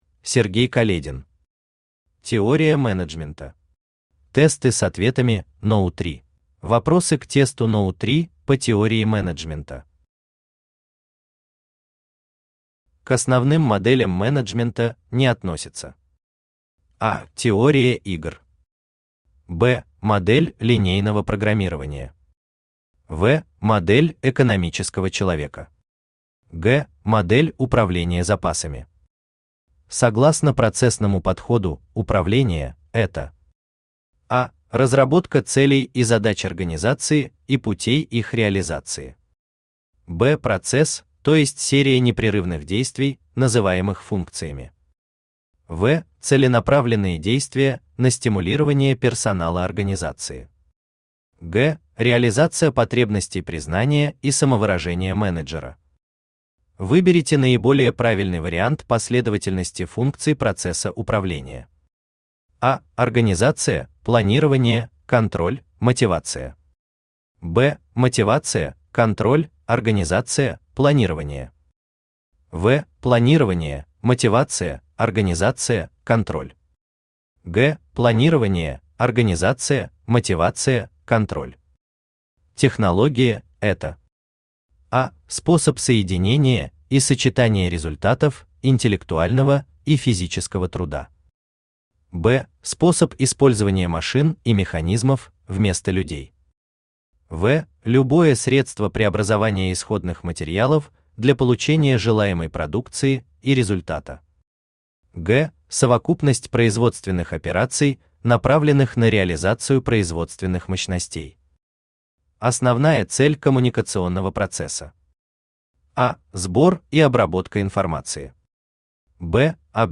Аудиокнига Теория менеджмента. Тесты с ответами № 3 | Библиотека аудиокниг
Тесты с ответами № 3 Автор Сергей Каледин Читает аудиокнигу Авточтец ЛитРес.